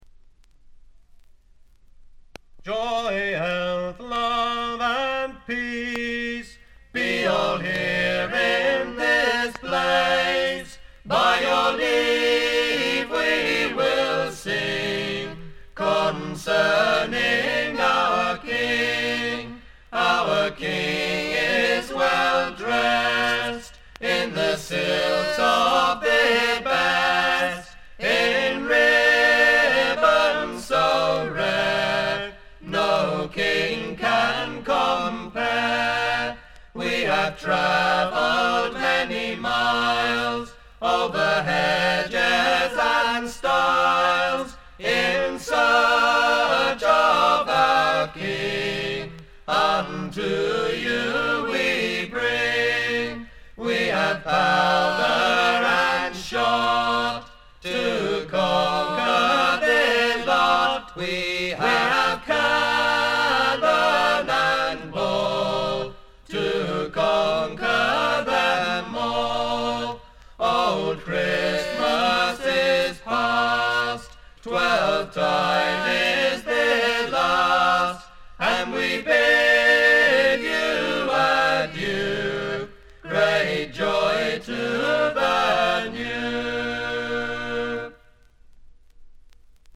B4冒頭プツ音2回。
英国エレクトリック・フォーク最高峰の一枚。
試聴曲は現品からの取り込み音源です。